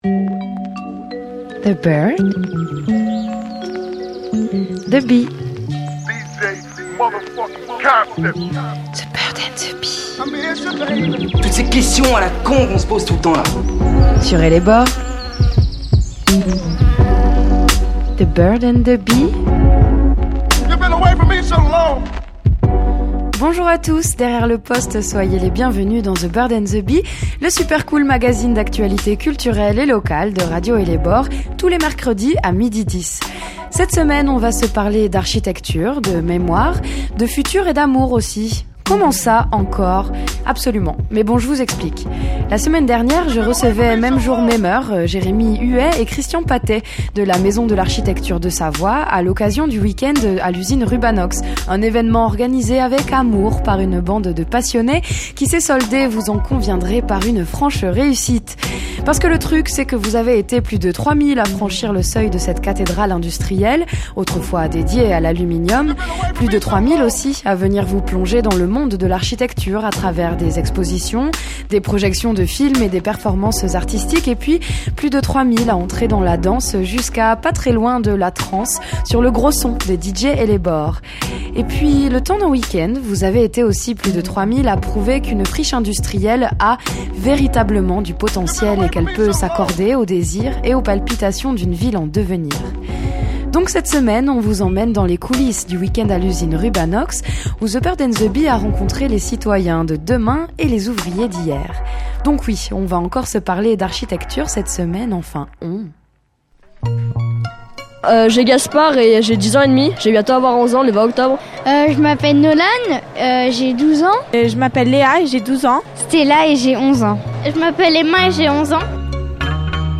Radio Ellebore a rencontré les ouvriers d’hier, les acteurs d’aujourd’hui et les citoyens de demain. Un reportage à écouter :